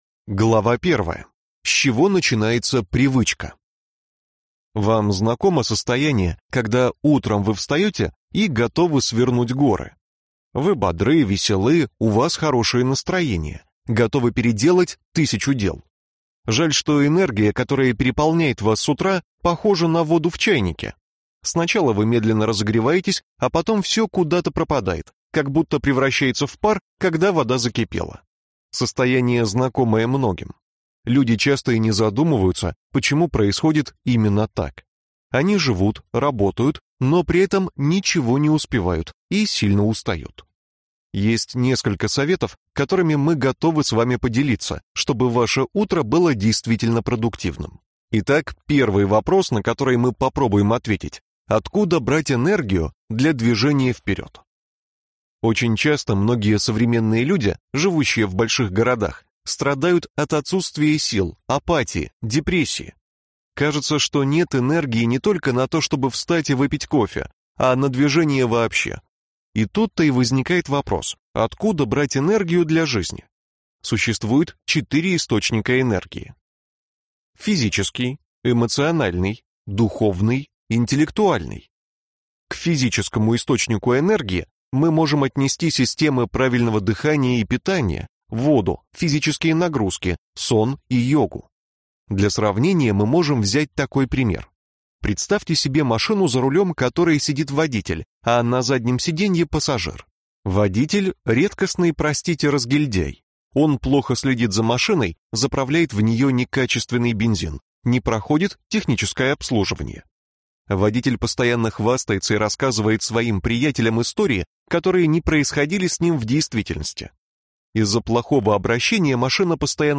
Аудиокнига Продуктивное утро | Библиотека аудиокниг
Прослушать и бесплатно скачать фрагмент аудиокниги